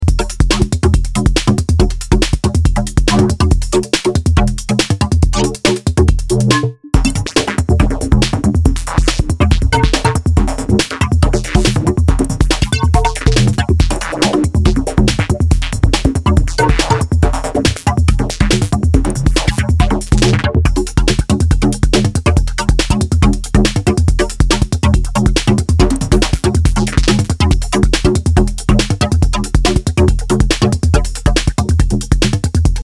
layered it under an ongoing WIP track, needs some proper mixwork but it somehow fits in